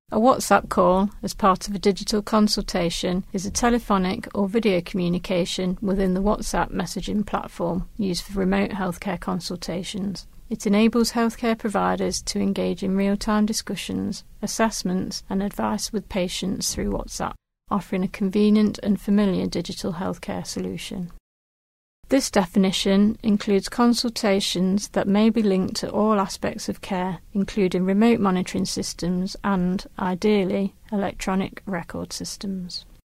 whatsapp_call.ogg